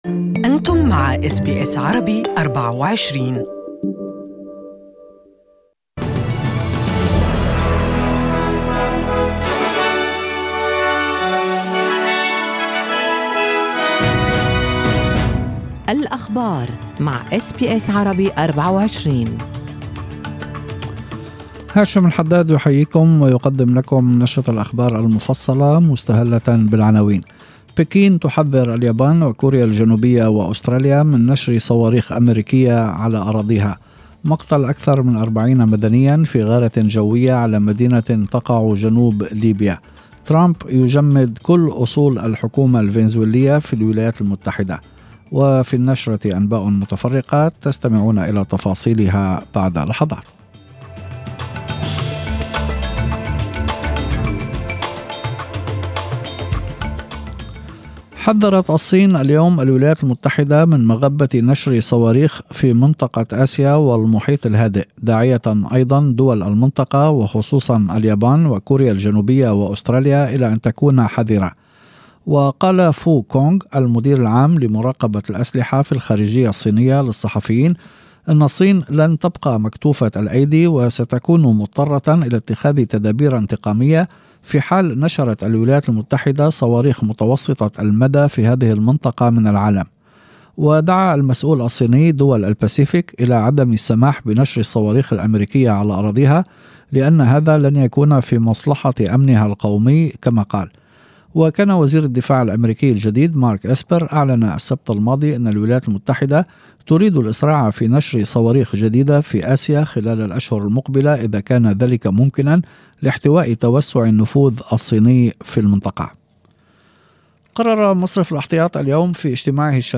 يمكن الاستماع لنشرة الأخبار المفصلة باللغة العربية في التقرير الصوتي أعلاه .